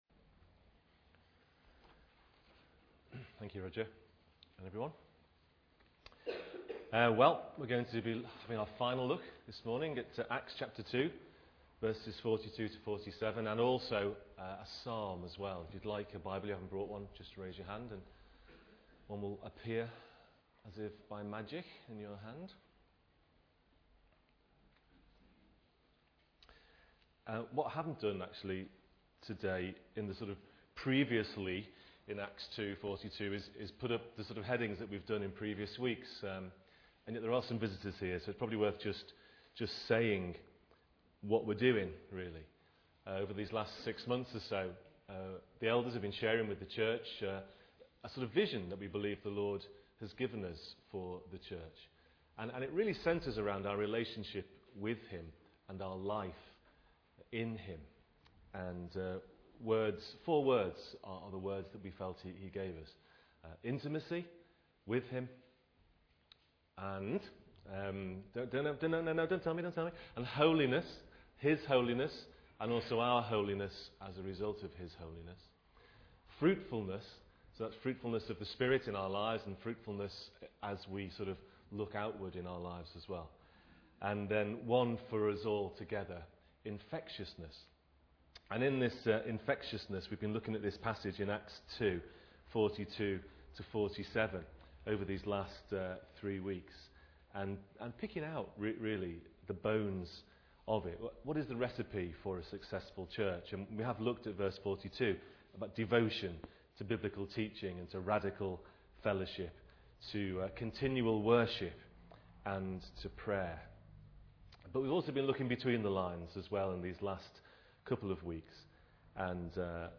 27 January 2008 35:46 Catching the vision for Saltisford View Sermons from all the years!